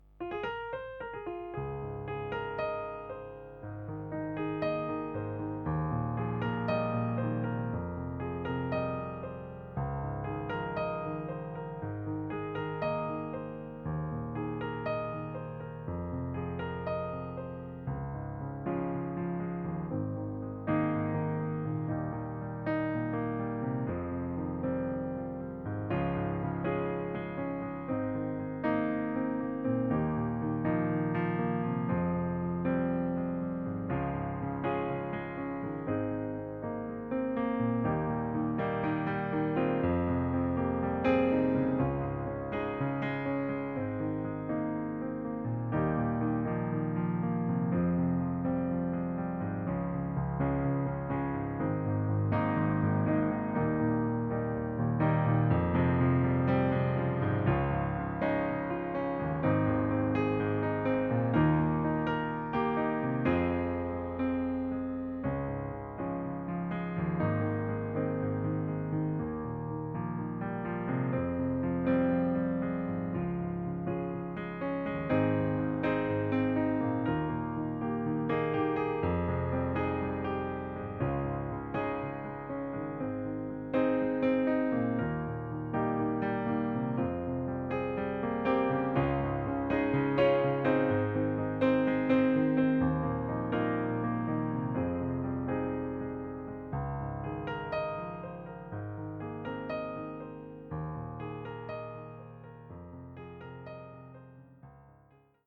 Demo in G#/Ab